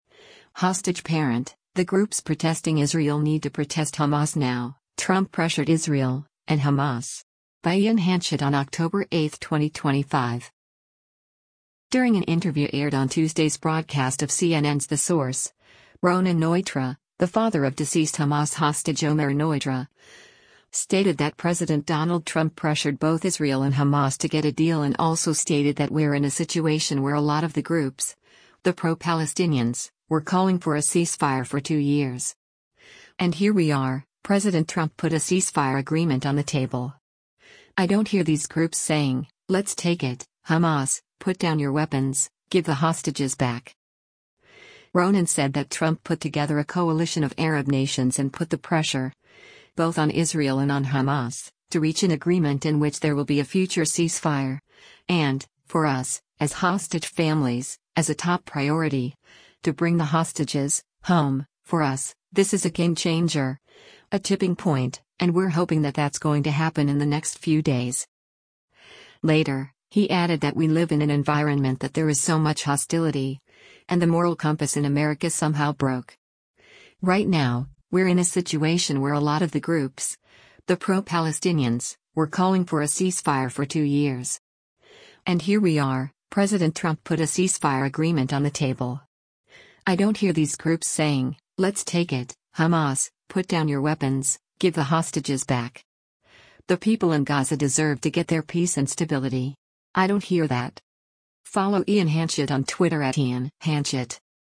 During an interview aired on Tuesday’s broadcast of CNN’s “The Source,”